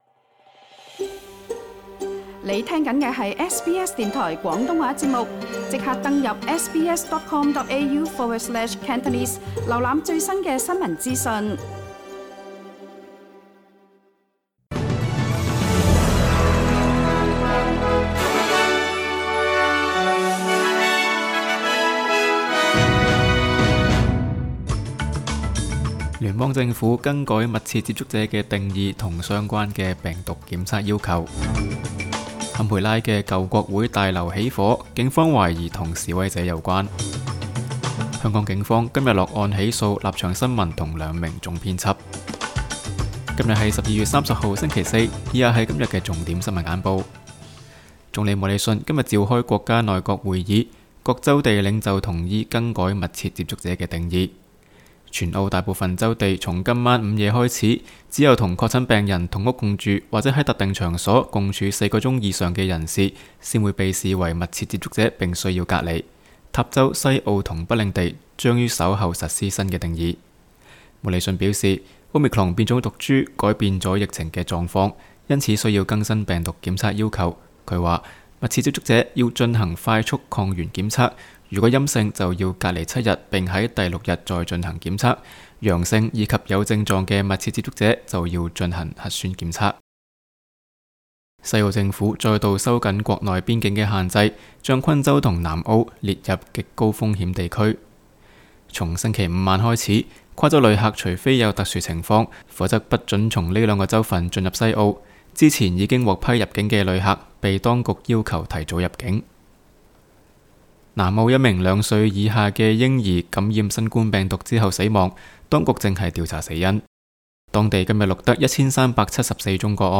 SBS 新闻简报（12月30日）
SBS 廣東話節目新聞簡報 Source: SBS Cantonese